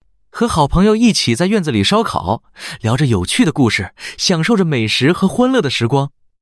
Happy.mp3